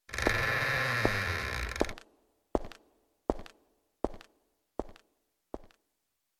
Door - Footsteps 1
bonus-sound Country door-creak film-production footsteps game-development Guitar intro sound effect free sound royalty free Music